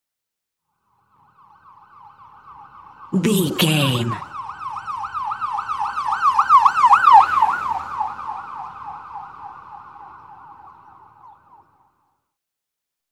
Ambulance Ext Passby Short Siren
Sound Effects
urban
chaotic
emergency